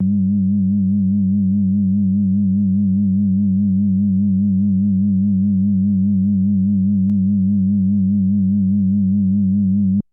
标签： FSharp4 MIDI音符-67 罗兰-SH-2 合成器 单票据 多重采样
声道立体声